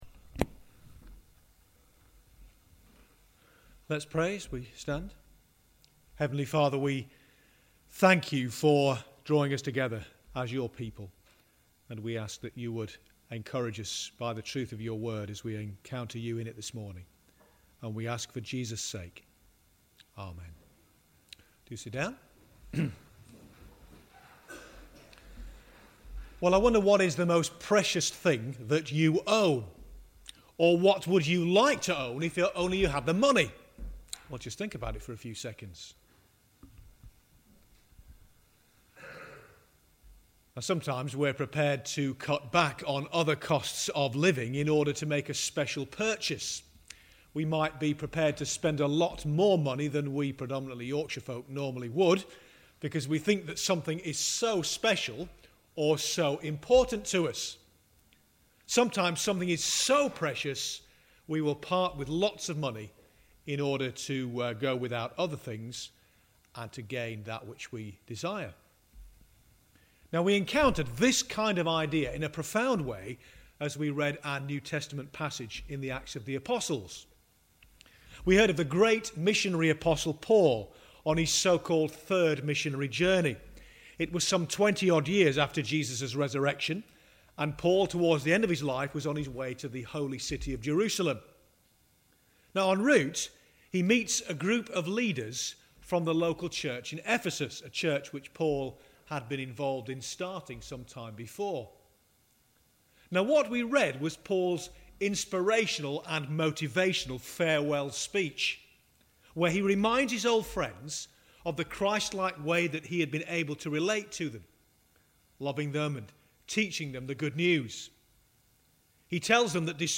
Sermon for the Annual Parochial Church Meeting Acts 20:22-32